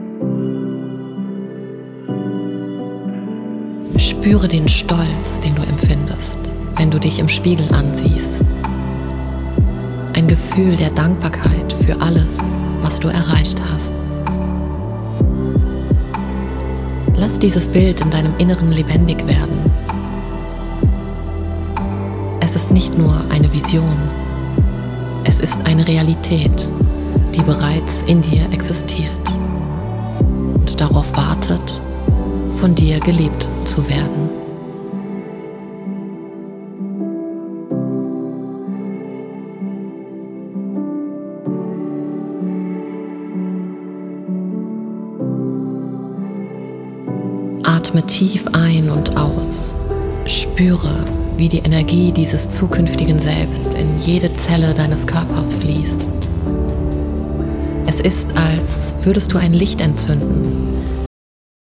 Frequenz: 432 Hz – Fördert körperliches Wohlbefinden, Harmonie und innere Ausgeglichenheit.
8D-Musik: Verstärkt die Visualisierung der Transformation, lässt die Klänge um dich fließen und unterstützt das Gefühl von positiver Veränderung und Motivation.